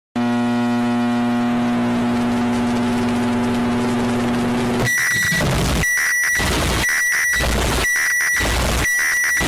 Microwave Earrape Sound Effect Free Download
Microwave Earrape